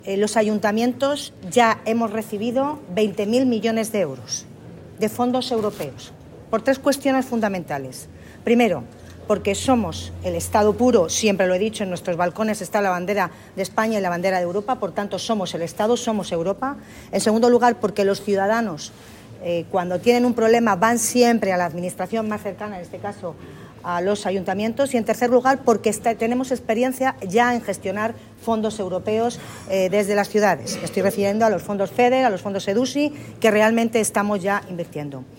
En declaraciones a los medios, Milagros Tolón ha destacado la importancia de esta jornada de divulgación y formación para que todo aquel interesado pueda acceder a esta herramienta de financiación procedente de Europa como ya lo están haciendo los Ayuntamientos de toda España que ya han recibido 20.000 millones de euros de los fondos Next Generation.